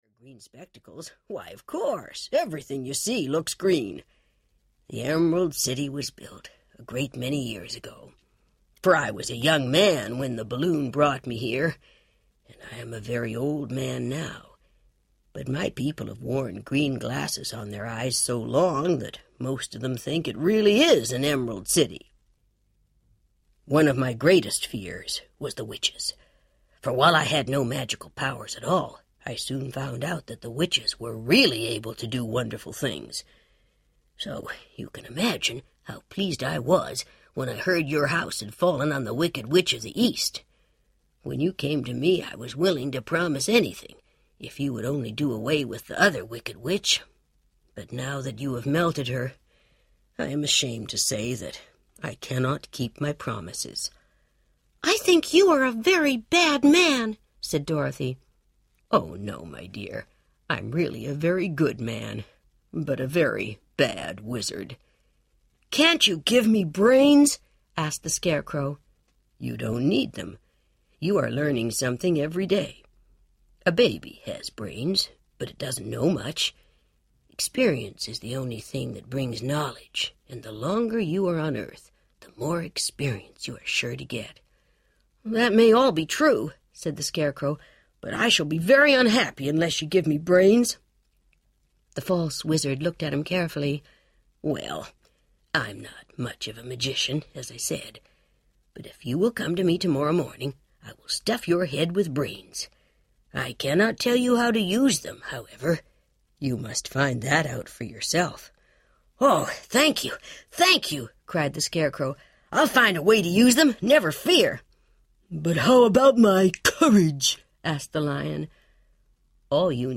The Wonderful Wizard of Oz Audiobook
Dorothy, the Scarecrow, the Tin Woodman and the Cowardly Lion are all here in technicolor – with some of the music that made the film unforgettable.
2.5 Hrs. – Abridged